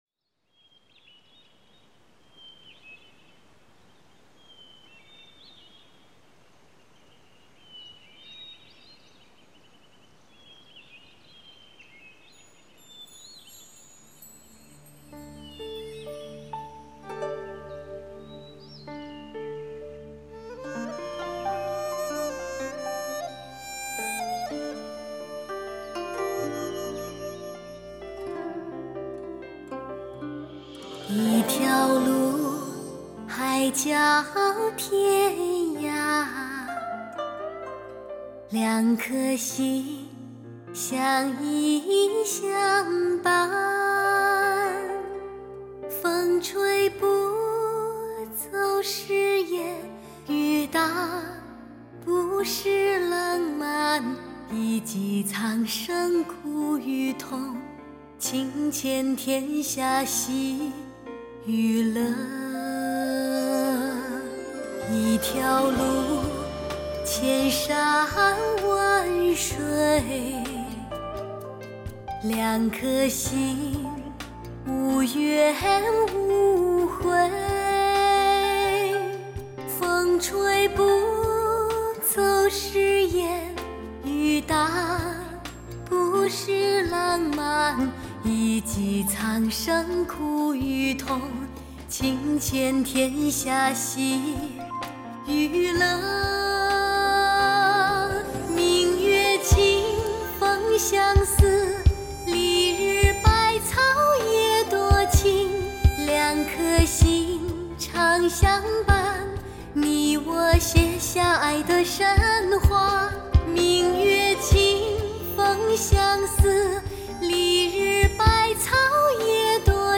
略带沙哑的性感嗓音恣情